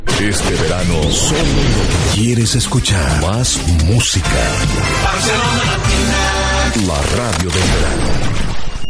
Indicatiu d'estiu de la ràdio